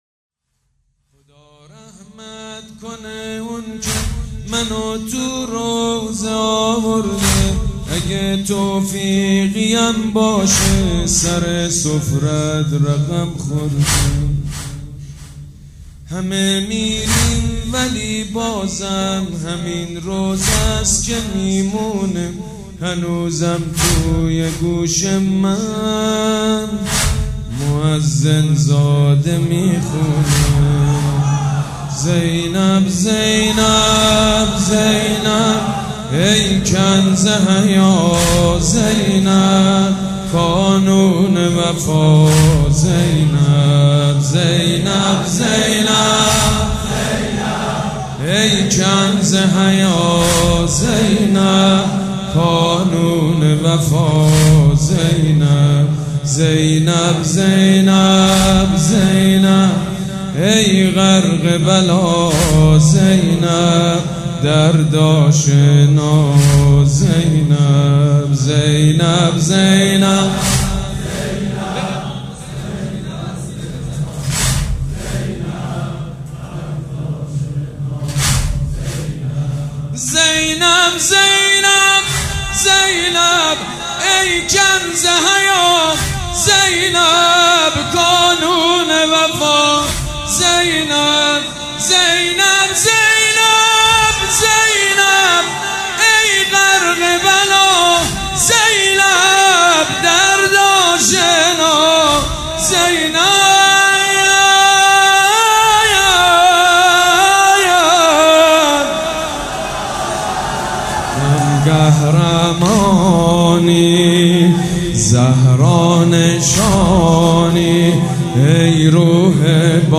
مداح
مراسم عزاداری شب دوم